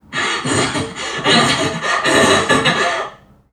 NPC_Creatures_Vocalisations_Robothead [49].wav